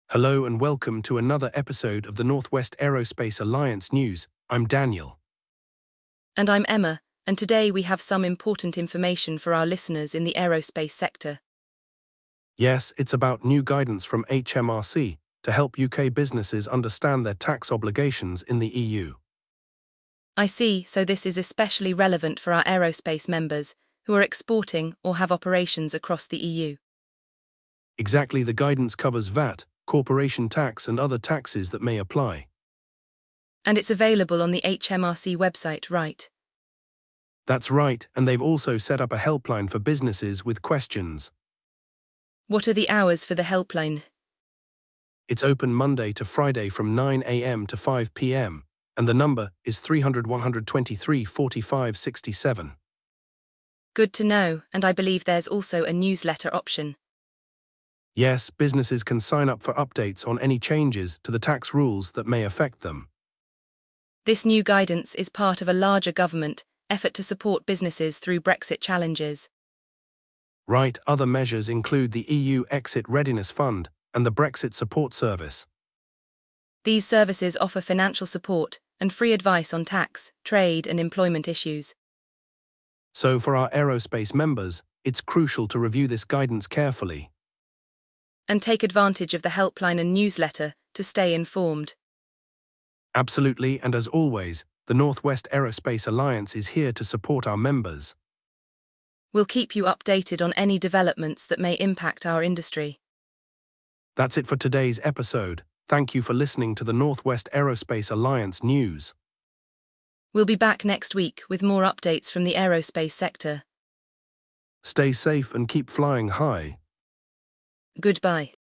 The hosts encourage aerospace members to review the guidance carefully and utilize the available resources to stay informed and compliant.